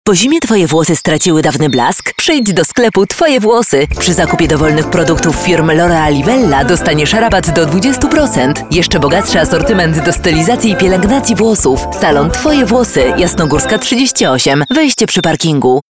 polnische Profi- Sprecherin für TV/Rundfunk/Industrie.
Sprechprobe: eLearning (Muttersprache):